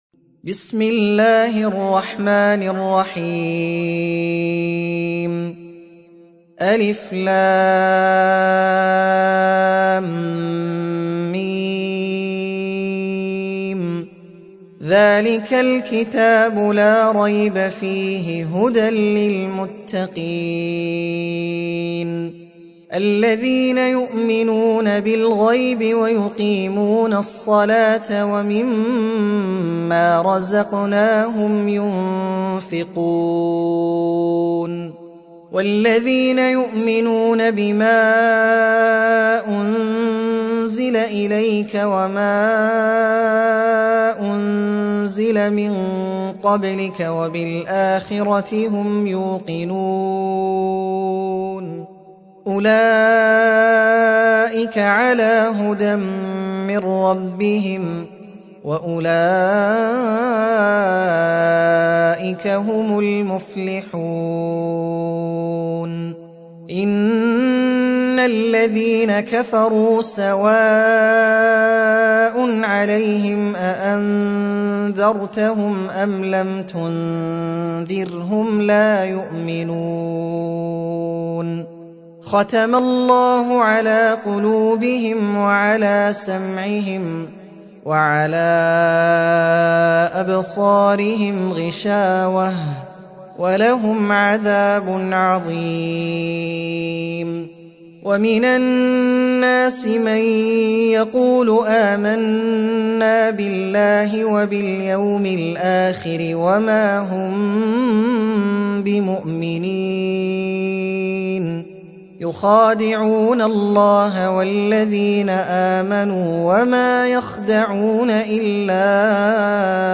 Sûrat Al-Baqarah (The Cow) - Al-Mus'haf Al-Murattal (Narrated by Hafs from 'Aasem)